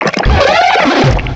-Replaced the Gen. 1 to 3 cries with BW2 rips.
lickilicky.aif